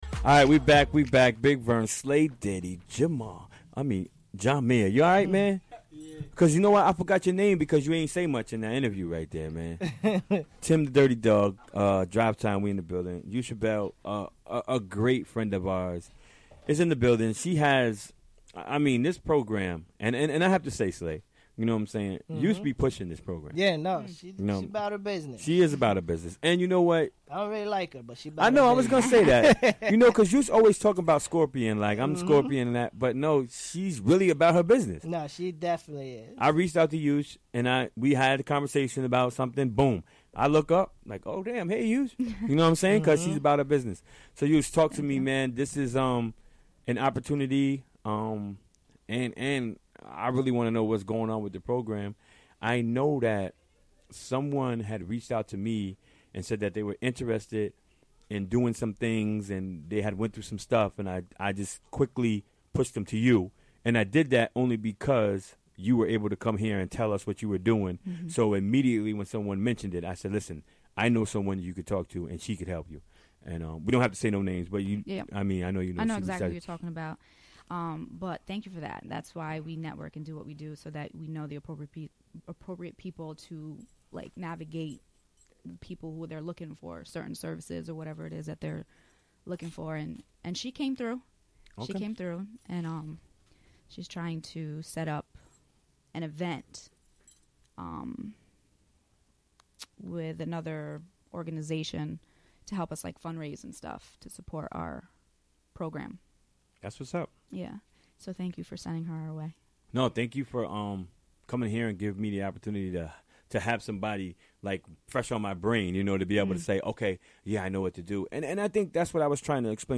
Recorded during the WGXC Afternoon Show Wednesday, February 1, 2017.